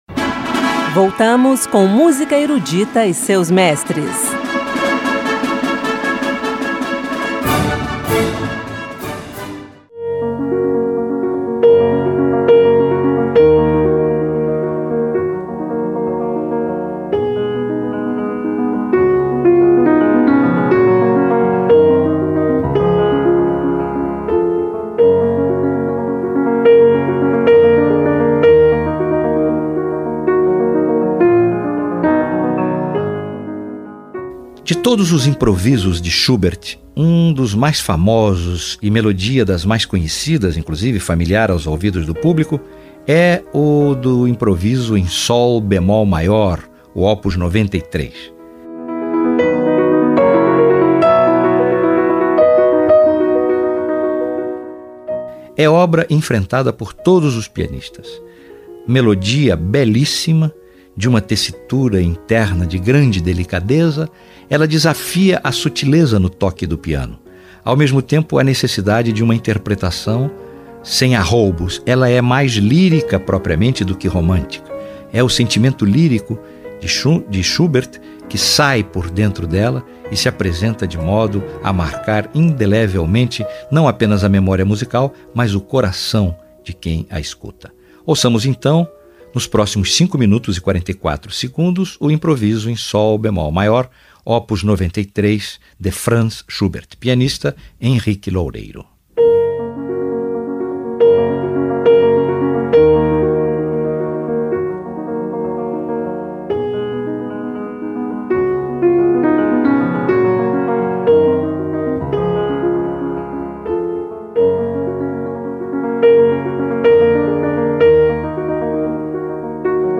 Música Erudita